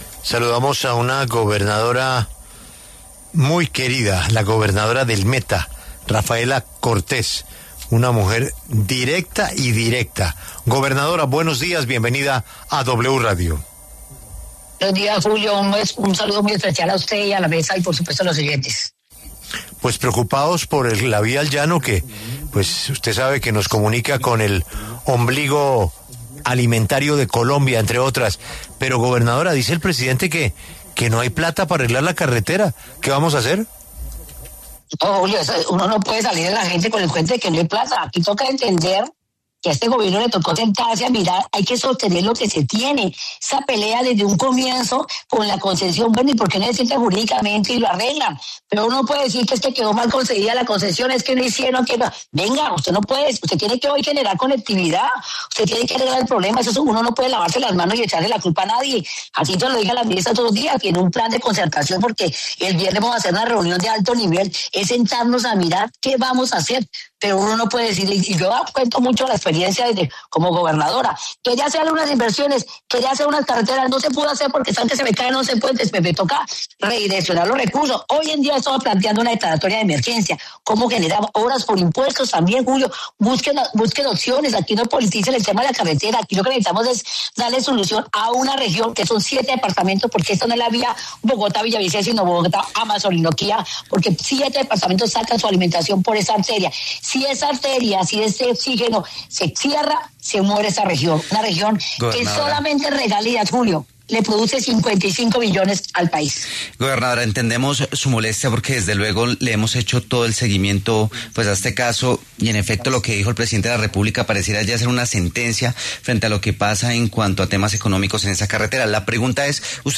Tras las declaraciones del presidente de la República, Gustavo Petro, en las que reconoció que no hay dinero para arreglar la vía entre Bogotá y Villavicencio, la gobernadora del Meta, Rafaela Cortés, se pronunció en los micrófonos de W Radio, exponiendo su inconformidad con dichas declaraciones y exigiendo que se busquen alternativas.